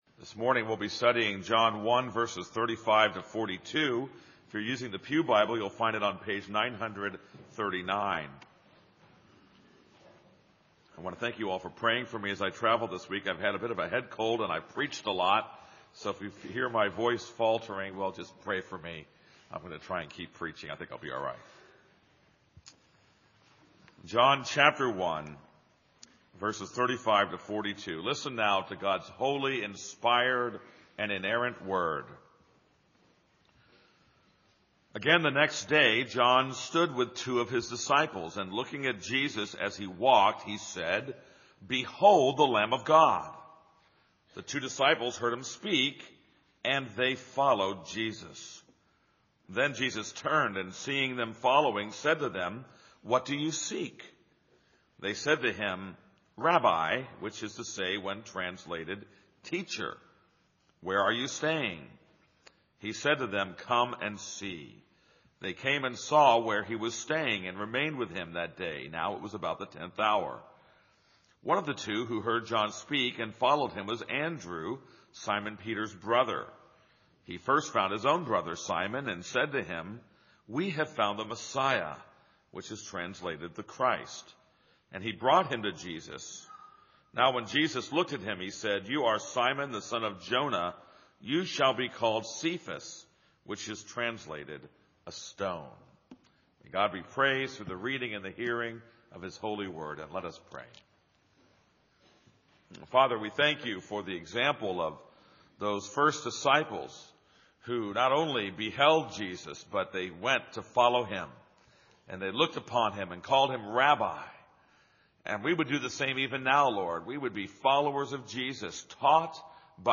This is a sermon on John 1:35-42.